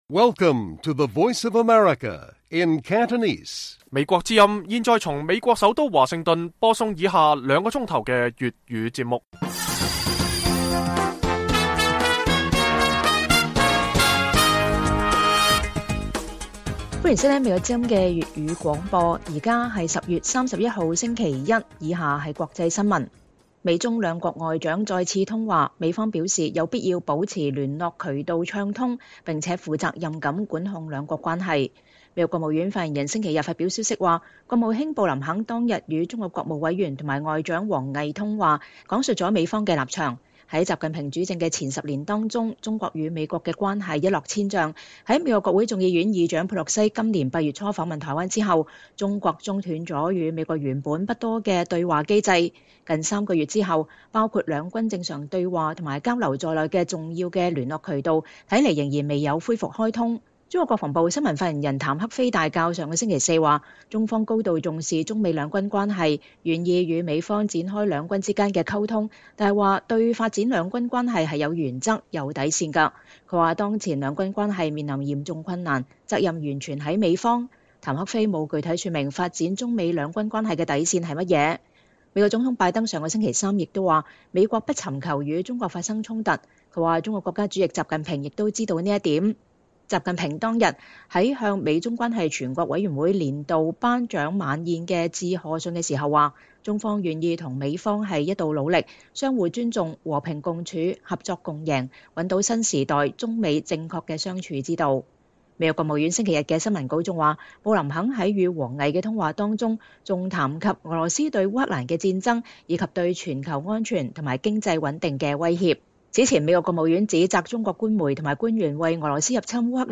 粵語新聞 晚上9-10點: 布林肯再與王毅通話 強調需要保持聯絡渠道暢通